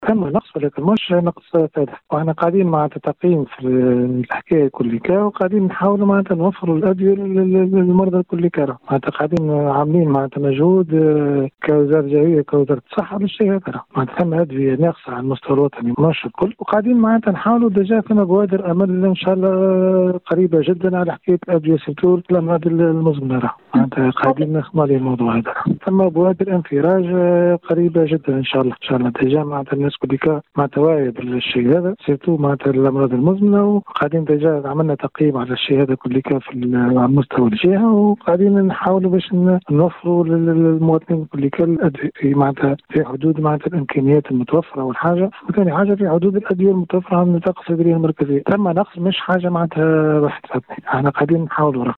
شربان : تشكيات من نقص الأدوية بالمستشفى المحلي ..المدير الجهوي للصحة على الخط (تسجيل) - Radio MFM